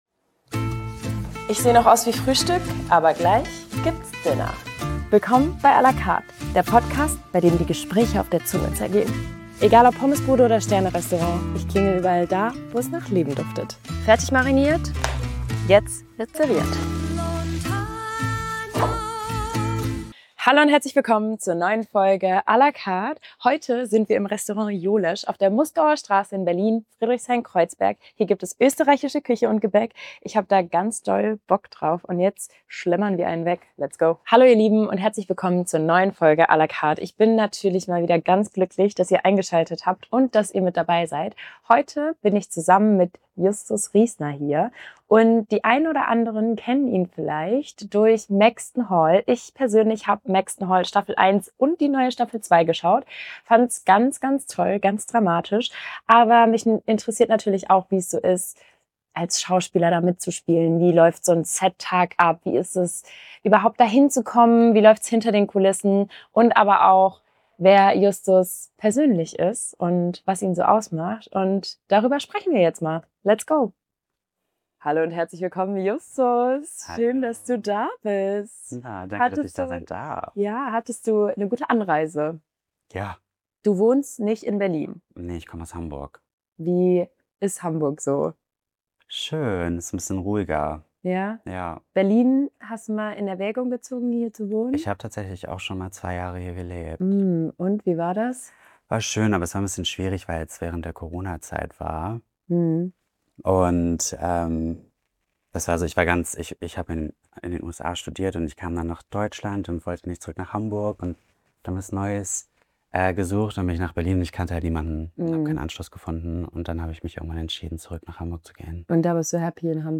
In dieser Folge ist Justus Riesner von Maxton Hall bei "à la Carte" im Restaurant Jolesch zu Gast. Bekannt als Alistair aus Maxton Hall beantwortet Justus nicht nur Fragen aus der Community, sondern lässt auch hinter die Kulissen blicken.